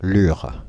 Ääntäminen
Ääntäminen France (Île-de-France): IPA: [lyʁ] Haettu sana löytyi näillä lähdekielillä: ranska Käännöksiä ei löytynyt valitulle kohdekielelle.